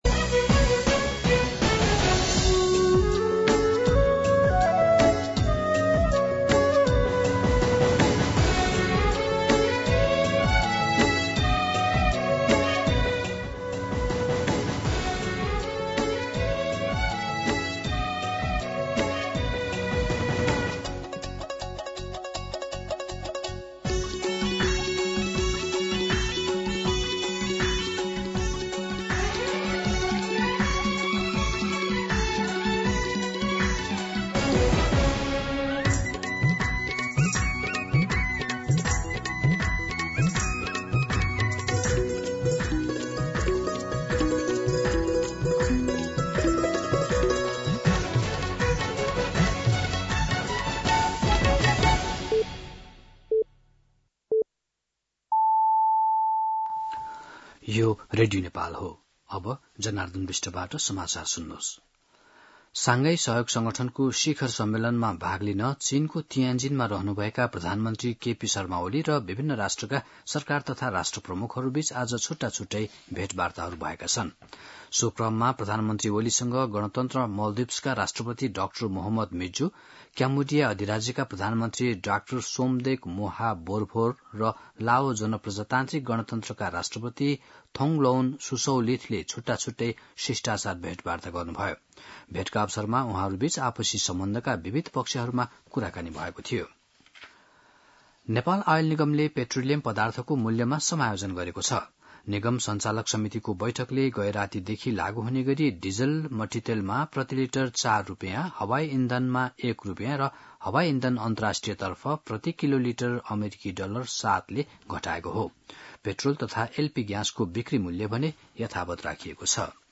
दिउँसो १ बजेको नेपाली समाचार : १६ भदौ , २०८२